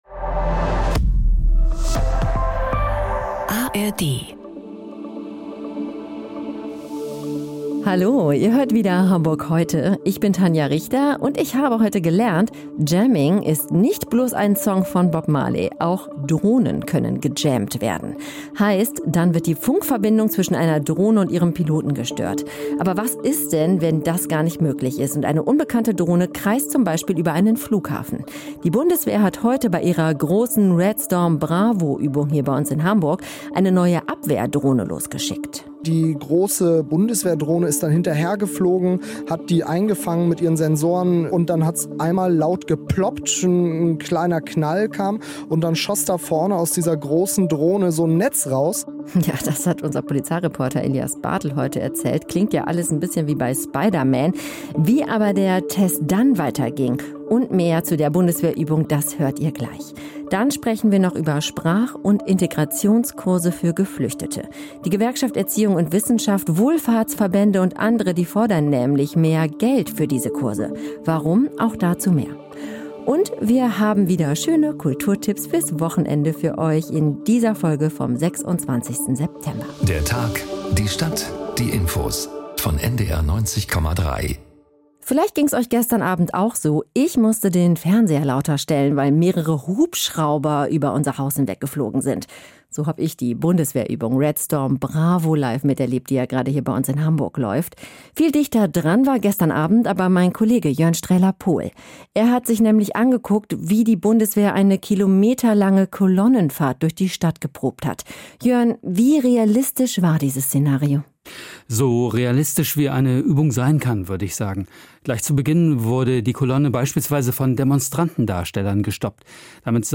Das sind die Nachrichten heute